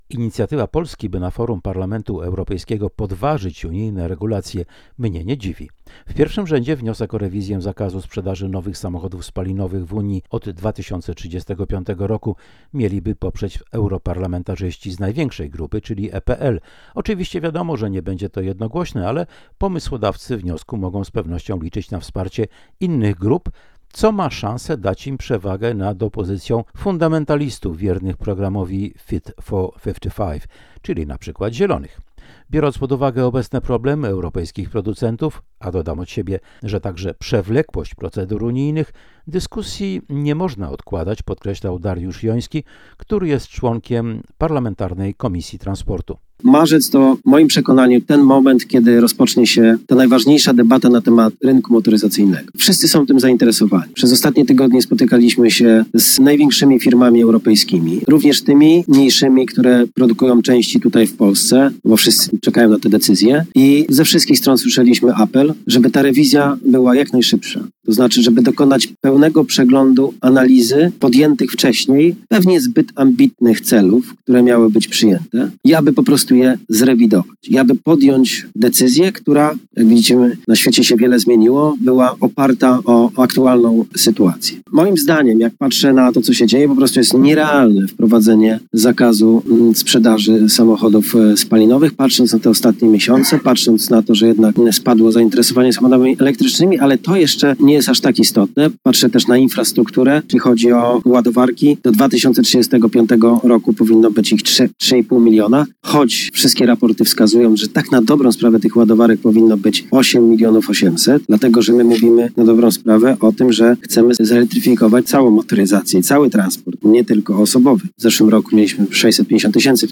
Polska jako jedyna głosowała przeciwko. Teraz okazuje się, że może znaleźć sojuszników, by zakaz odsunąć w czasie lub go ograniczyć. Mówił dziś o tym w Biurze Parlamentu Europejskiego w Warszawie europoseł Dariusz Joński.